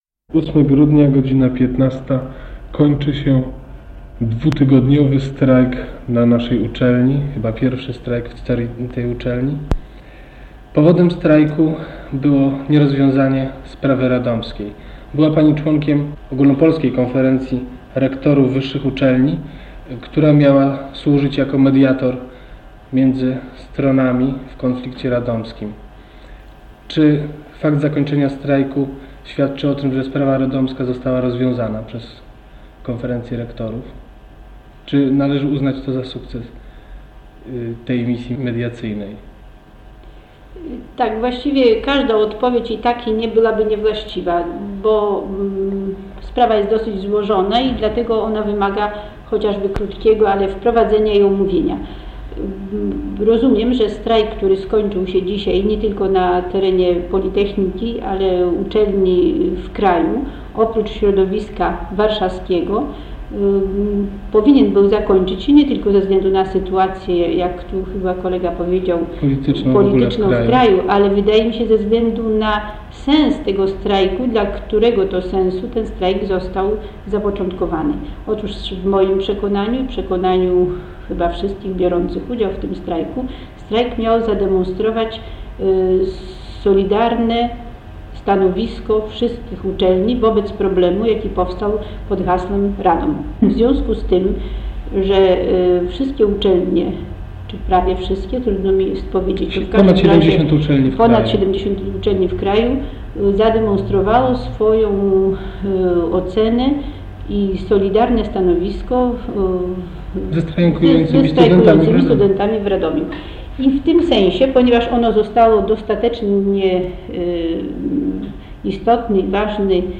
Rozmowa
w studiu SAR